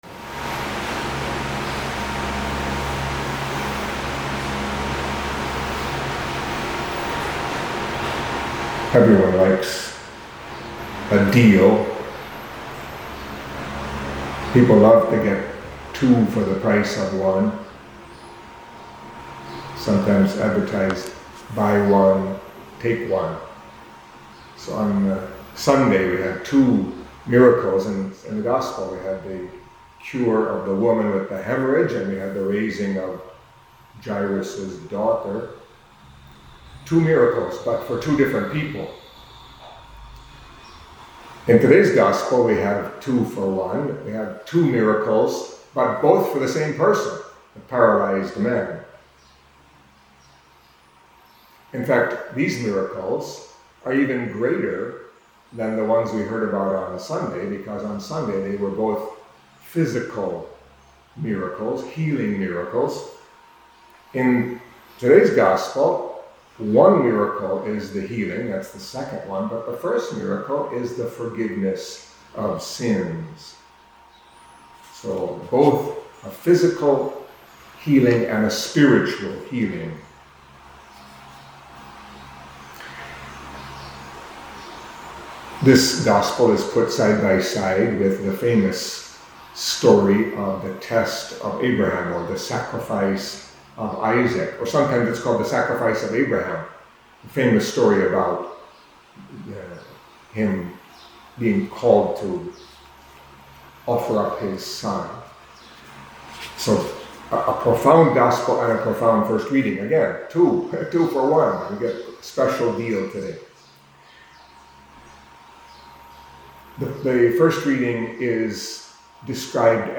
Catholic Mass homily for Thursday of the 13th Week in Ordinary Time